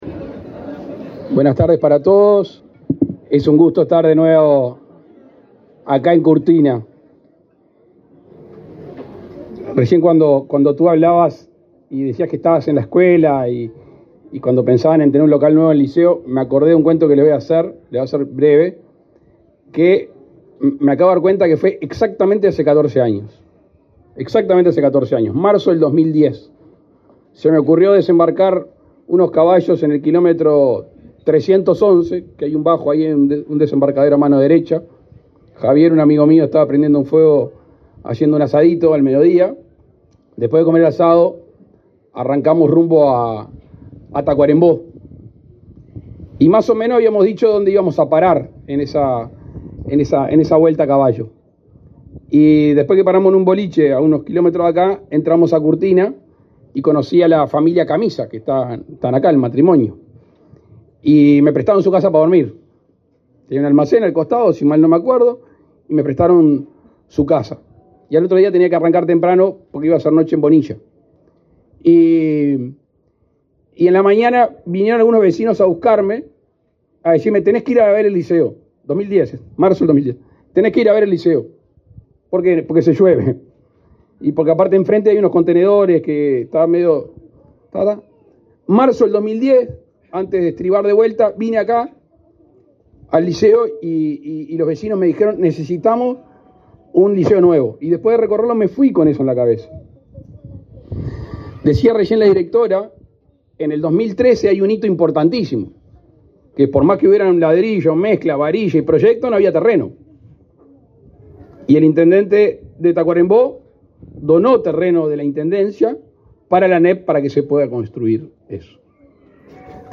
Palabras del presidente de la República, Luis Lacalle Pou
Con la participación del presidente de la República, Luis Lacalle Pou, se realizó, este 7 de marzo, el acto de inauguración del edificio del liceo